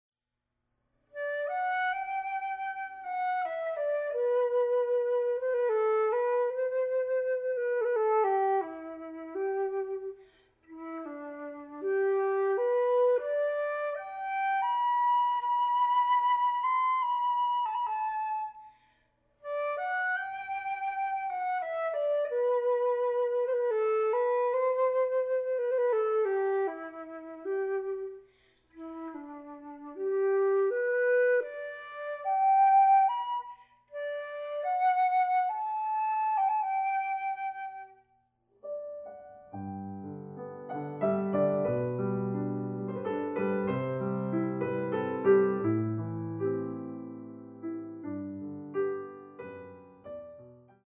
recorded live
pianist